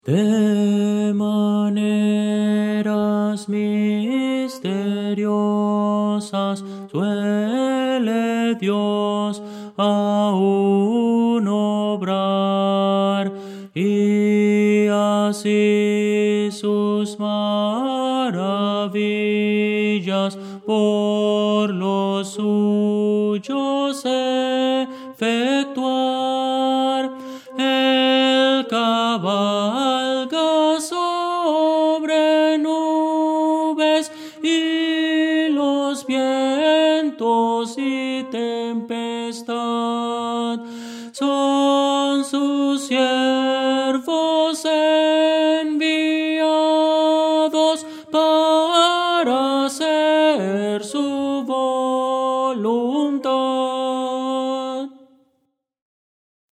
Tenor – Descargar